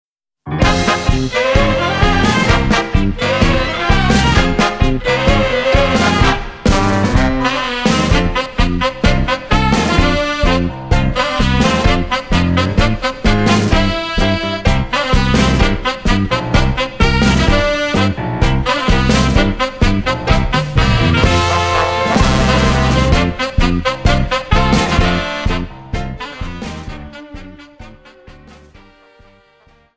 TANGO:32(4+64)